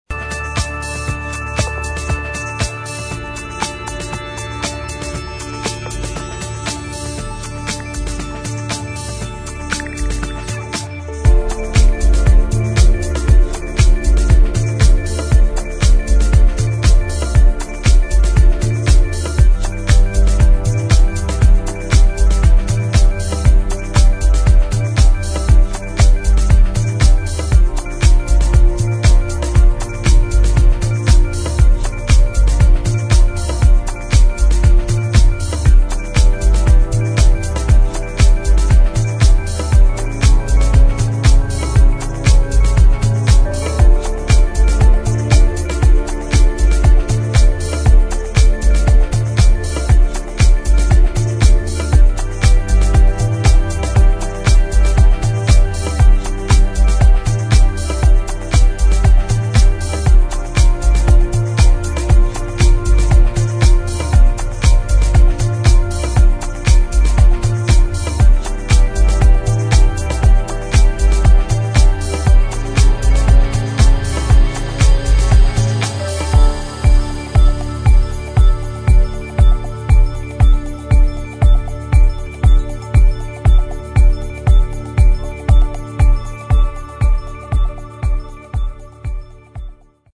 [ HOUSE / TECHNO ]